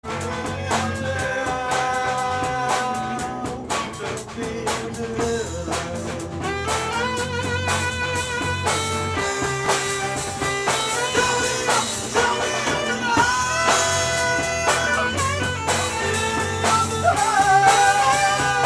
Enregistrement mini-disc (29.12.2001)